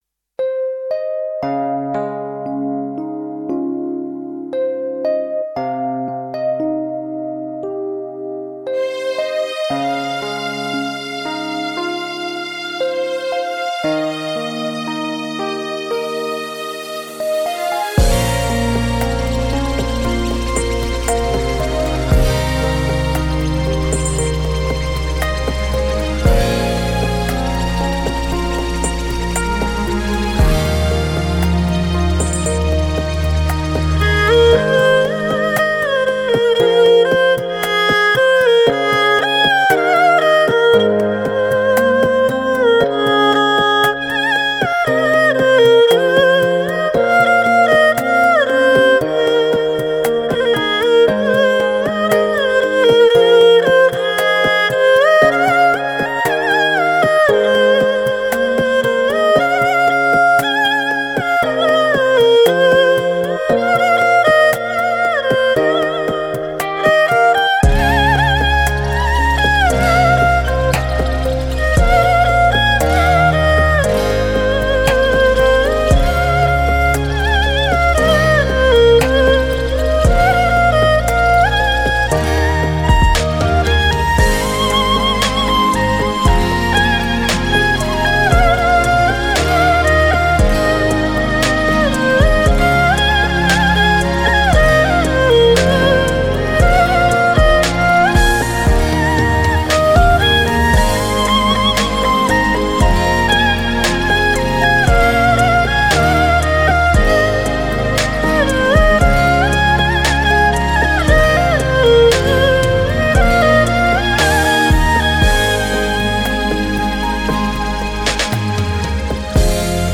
二胡演奏
聆听悠扬如诗般的清新天籁之音